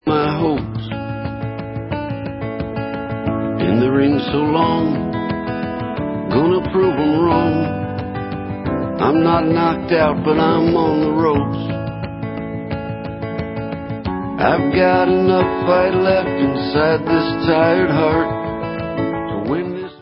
indie-rocková kapela